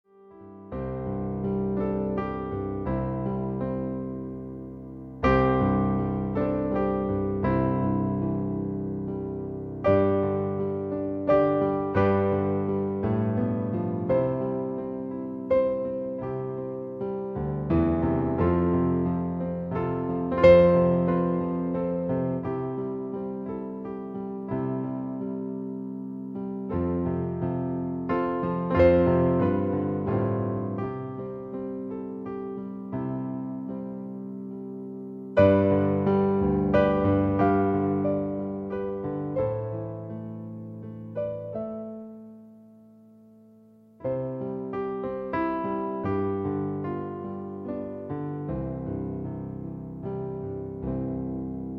• Качество: 128, Stereo
красивые
без слов
инструментальные
фортепиано
рождественские
саундтрек